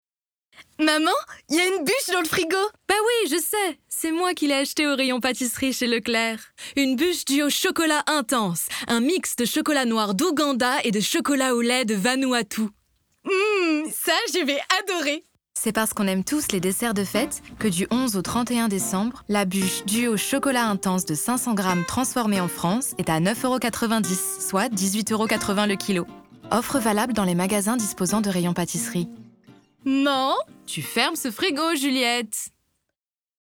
Démo voix - Pub radio - voix 2 (mère) et 3 (conditions générales)
8 - 50 ans - Mezzo-soprano